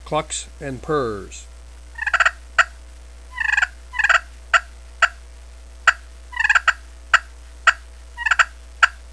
Listen to 9 seconds of clucks & purrs
High-Frequency Glass Call
primoscrystalcluckspurrs9.wav